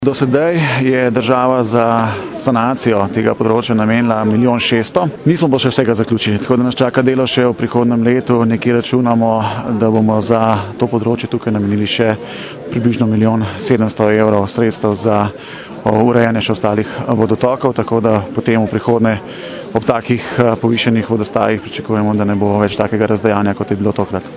izjava_simonzajcministerzaokoljeinprostor.mp3 (591kB)